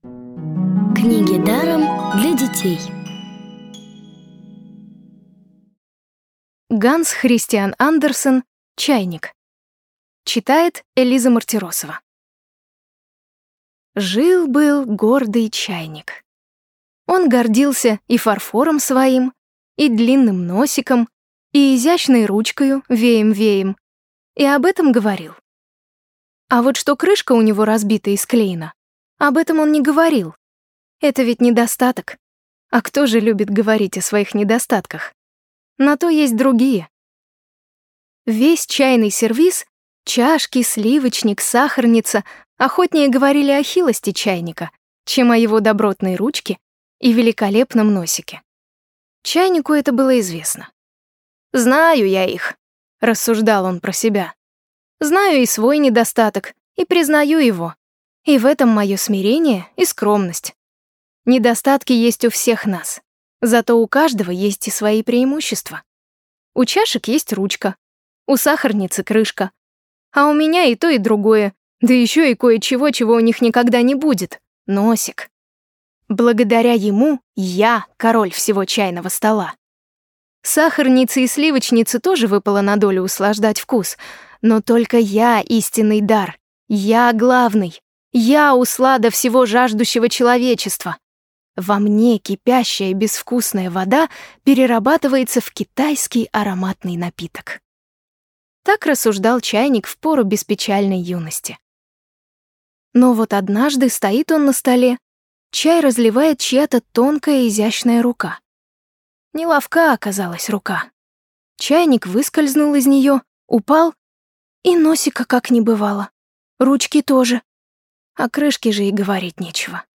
Главная Аудиокниги Для детей
Аудиокниги онлайн – слушайте «Чайник» в профессиональной озвучке и с качественным звуком.